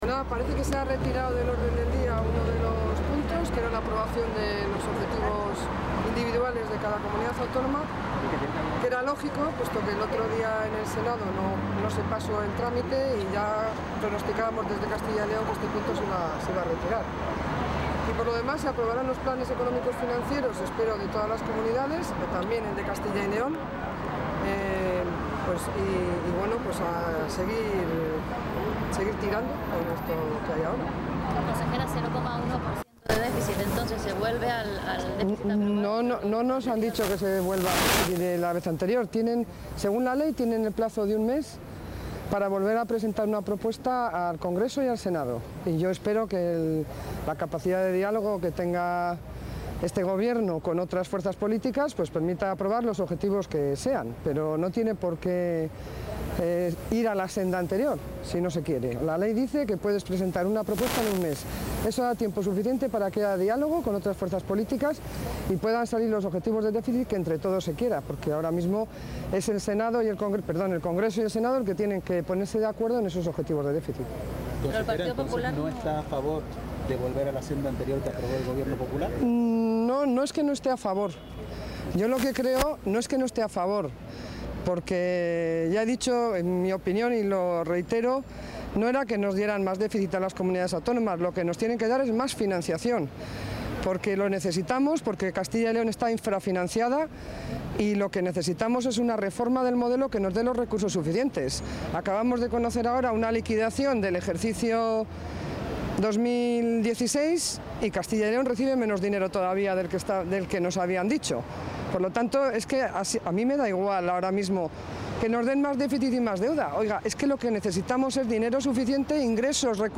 Declaraciones de la consejera de Economía y Hacienda antes del Consejo de Política Fiscal y Financiera